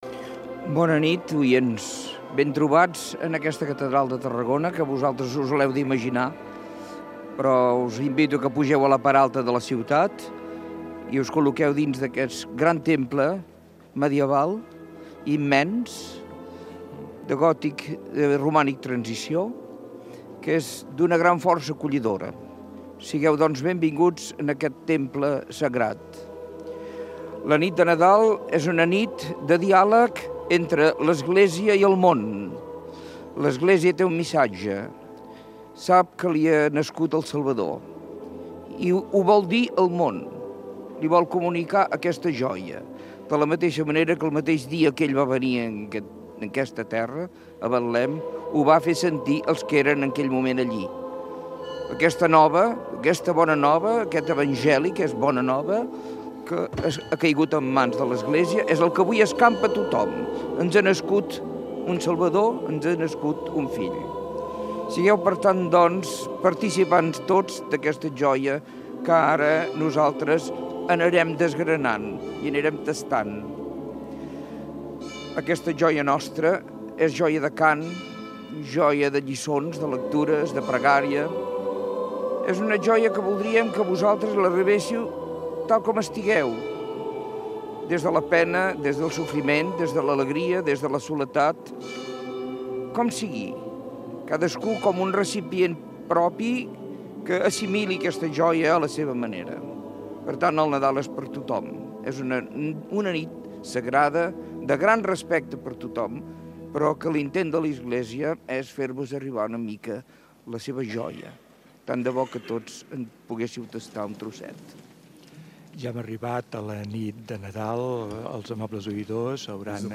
Transmissió des de la Catedral de Tarragona de la Missa del gall oficiada per Monsenyor Jaume Pujol, Arquebisbe de Tarragona.
Religió